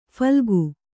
♪ phalgu